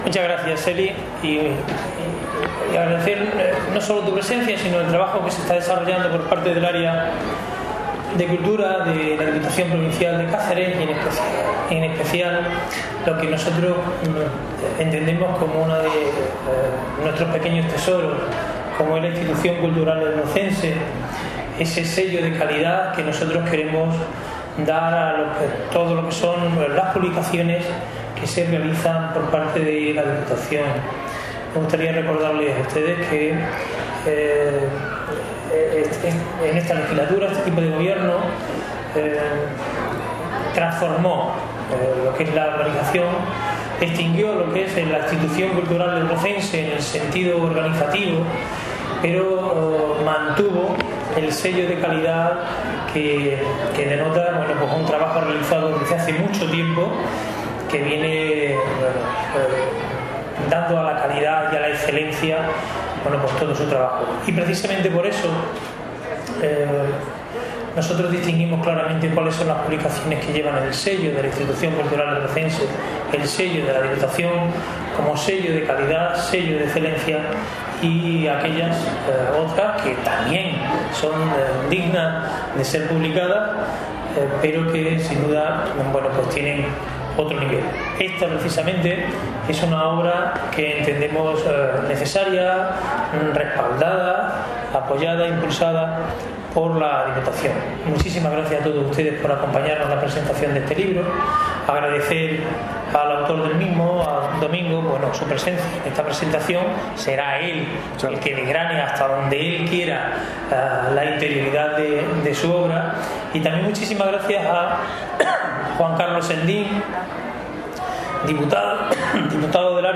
CORTES DE VOZ
FERIA_LIBRO_ESTUDIOS_LOCALES.mp3